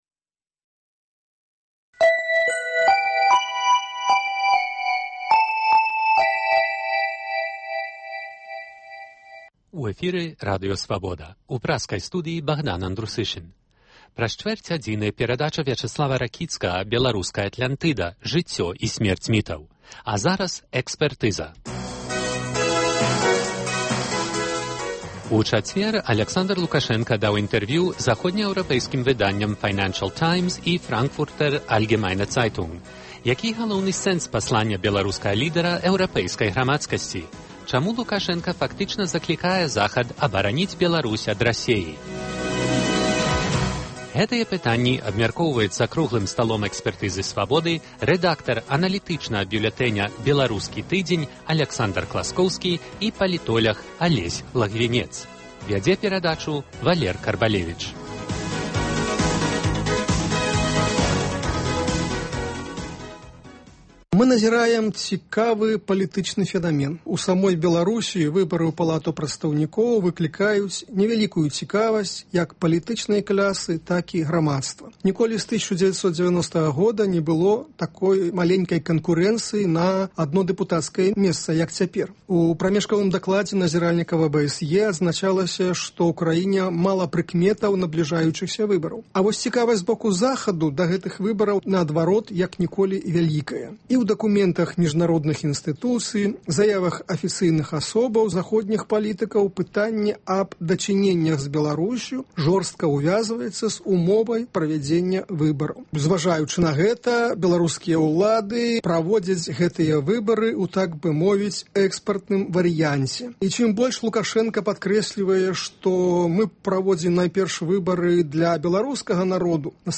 Гутарка экспэртаў за круглым сталом